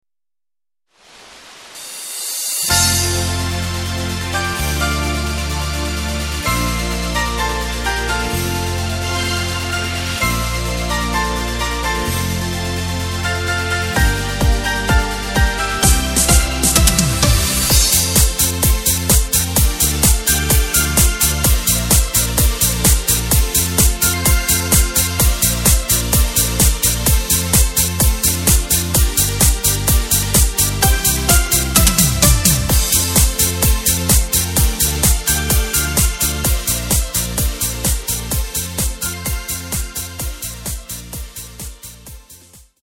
Tempo:         120.00
Tonart:            F
Discofox aus dem Jahr 2022!
Playback mp3 Demo